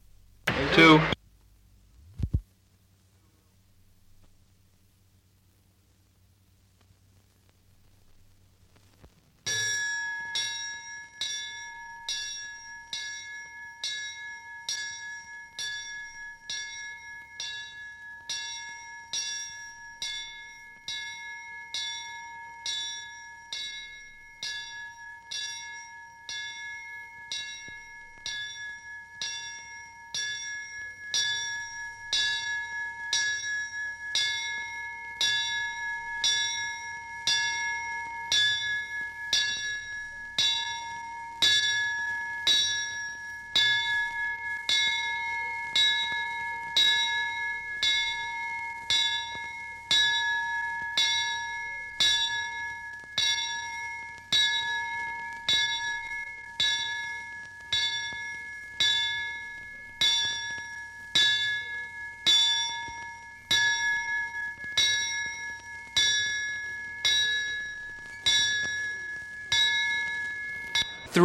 古老的钟声和蜂鸣器 " G2024火灾报警器
描述：火警铃。长时间稳定的机械振铃，轻微的嗡嗡声。停止结束并响起。 这些是20世纪30年代和20世纪30年代原始硝酸盐光学好莱坞声音效果的高质量副本。 40年代，在20世纪70年代早期转移到全轨磁带。我已将它们数字化以便保存，但它们尚未恢复并且有一些噪音。
标签： 贝尔 复古 编钟
声道立体声